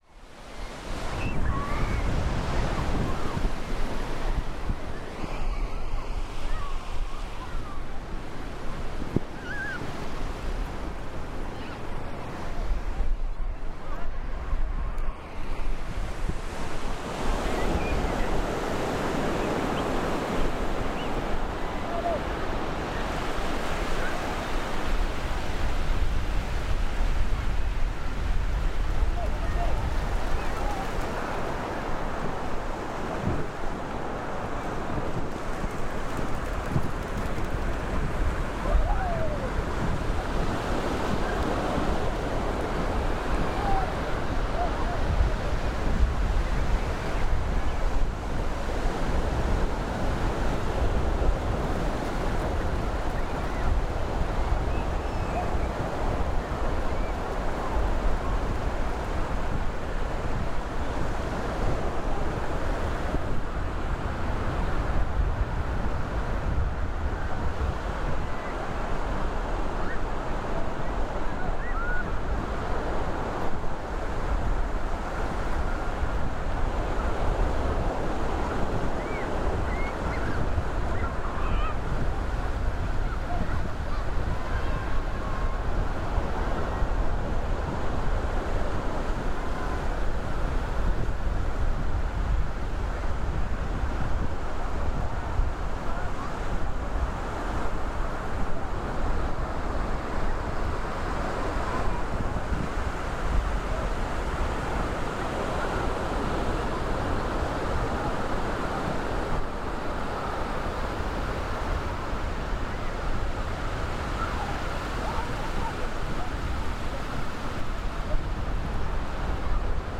A windy morning in Essaouira
A breezy beach walk in Essaouira, Morocco.